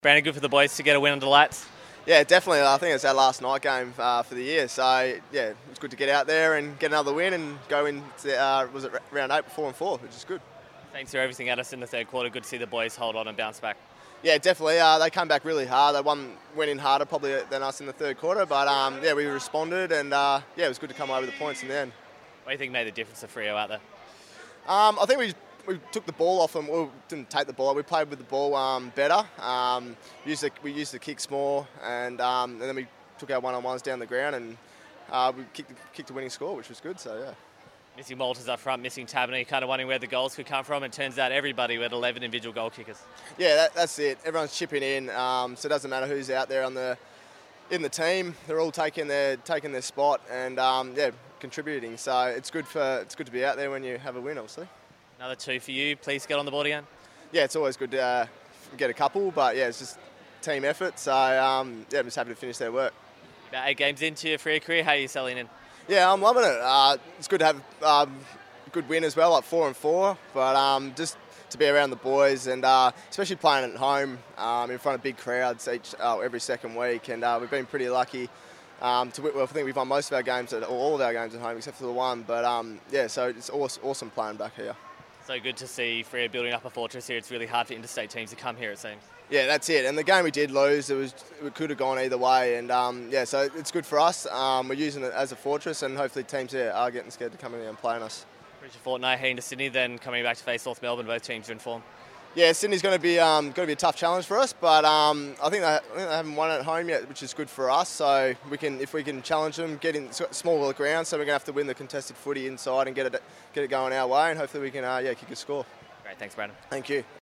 Brandon Matera post-match interivew - Round 8 v St Kilda